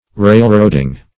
Railroading \Rail"road`ing\, n.